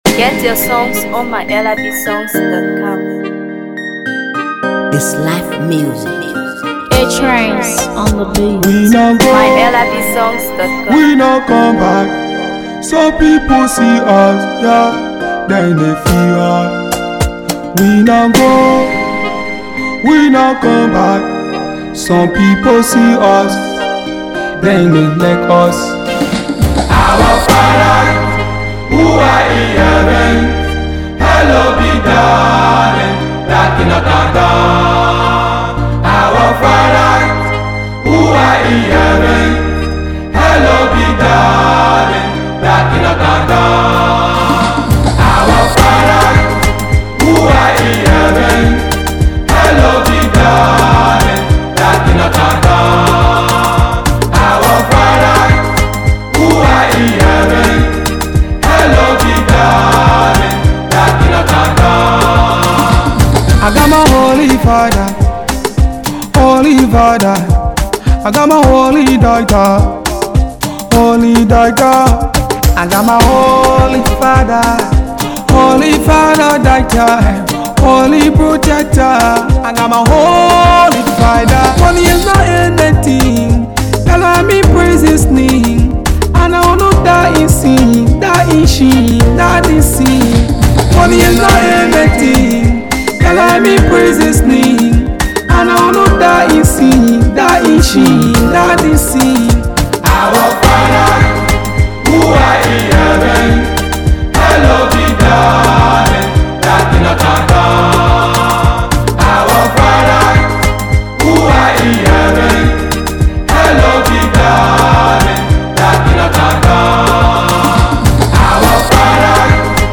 Afro PopGospel